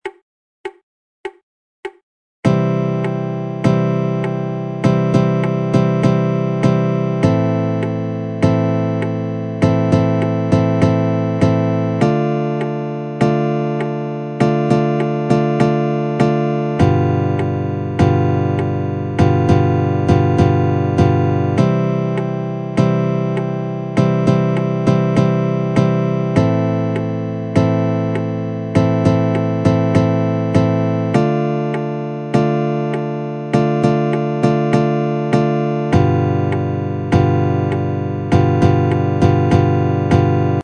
I have had to very slightly alter some patterns to fit within the two bar rhythm. let's start by learning the rhythm, it's a simple I-VI-II-V progression in the key of C major.
Arpeggio exercise chart – Rhythm only
arpeggio-exercise-rhythm.mp3